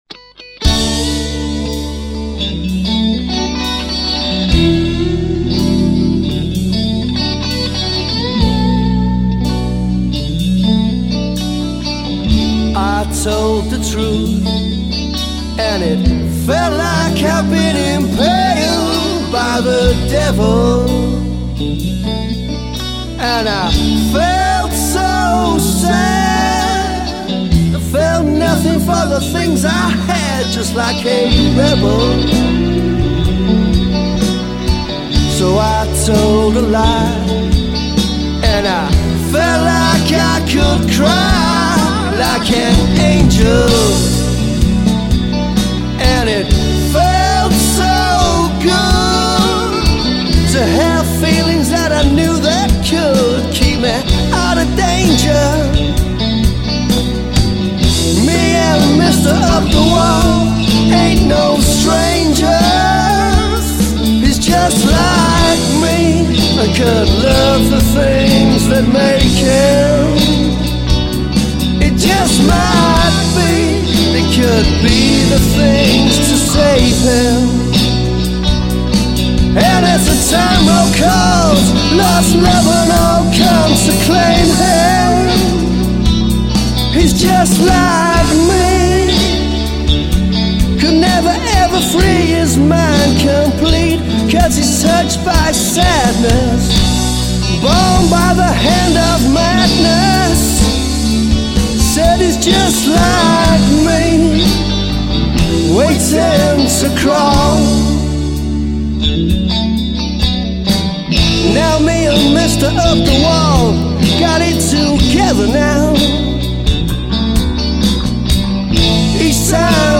I play the bass and moan.